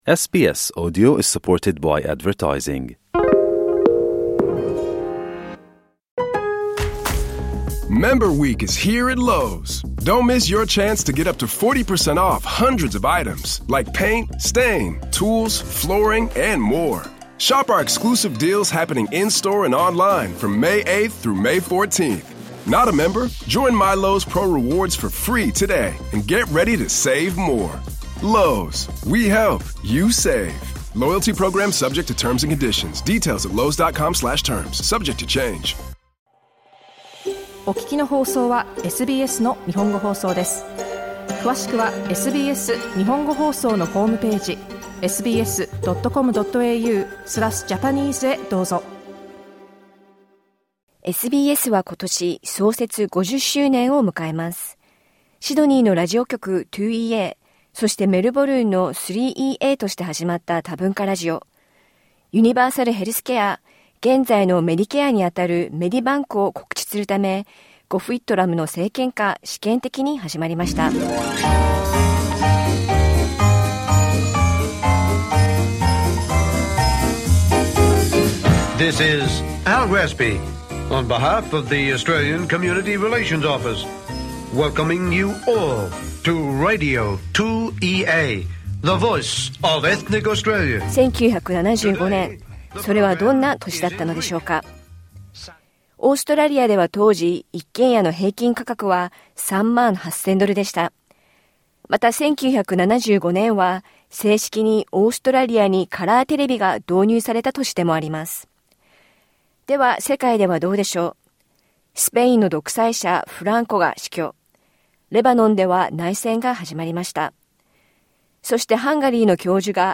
SBS celebrates its 50th anniversary this June. Join us as we revisit 1975 to 1985 through rare and memorable audio from the past.